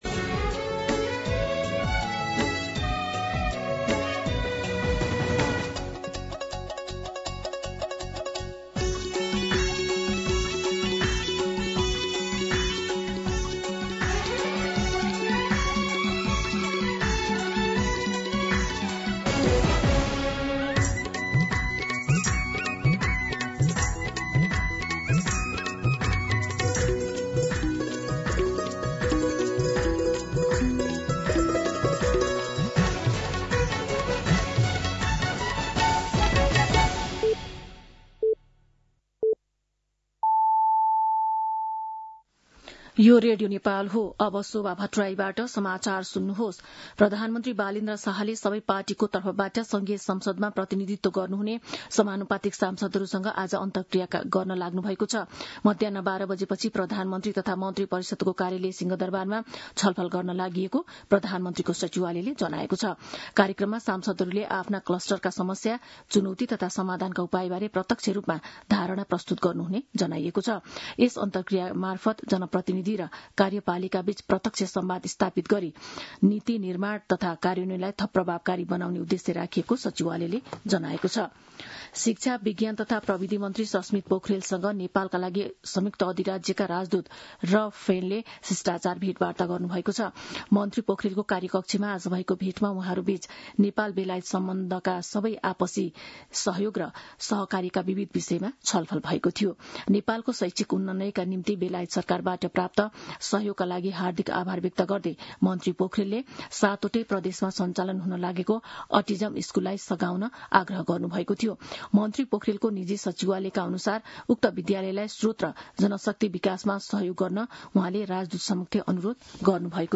मध्यान्ह १२ बजेको नेपाली समाचार : २ वैशाख , २०८३
12pm-News-1-2.mp3